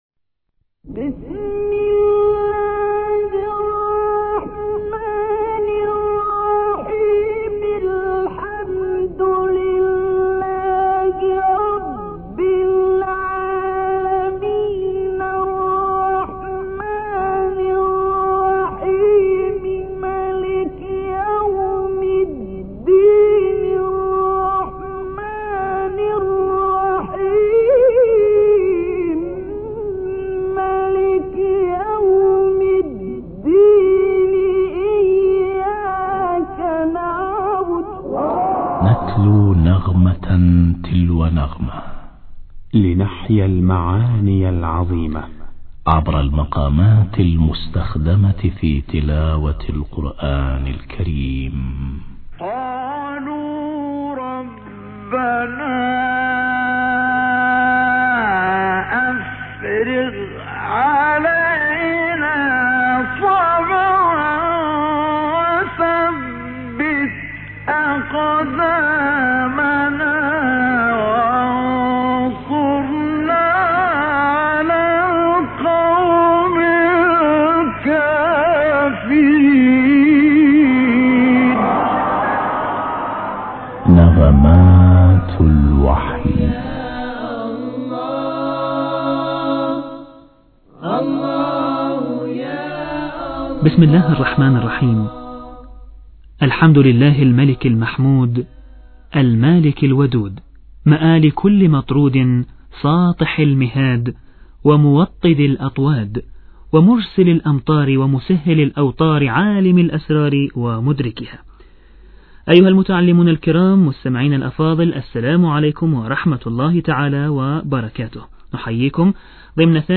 نغمات الوحي- تعليم مقامات تلاوة القران